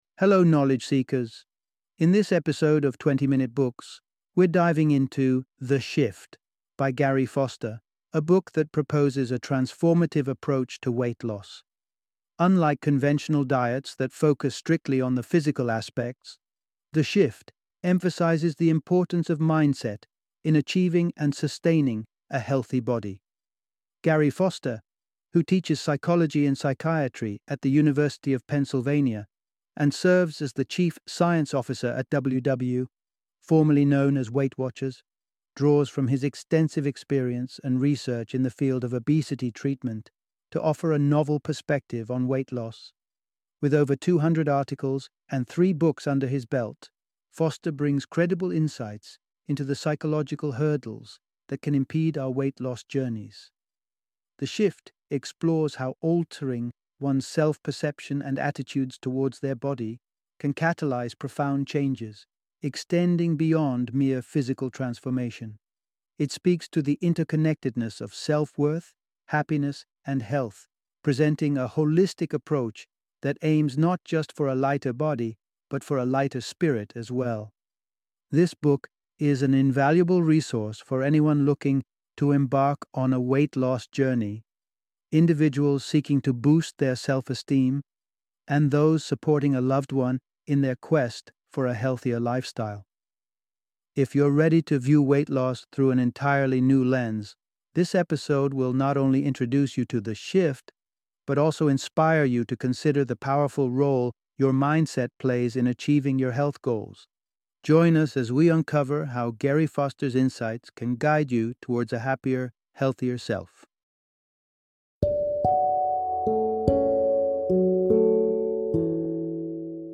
The Shift - Audiobook Summary